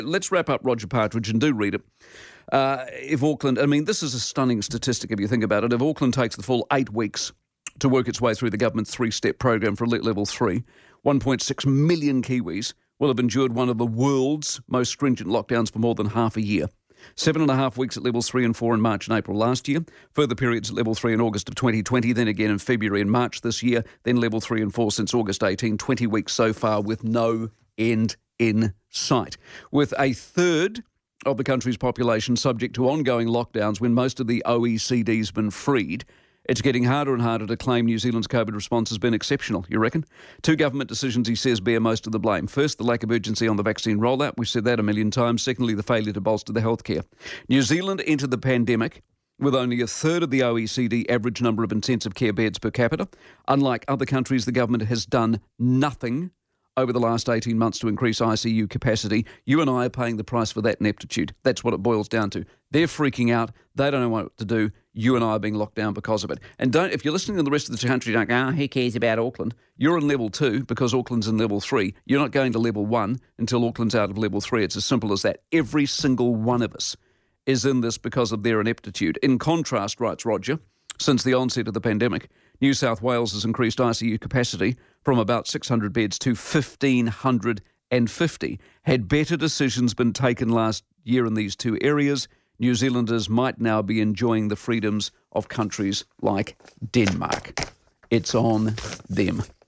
On Newstalk ZB,  Mike Hosking reviews and reads out parts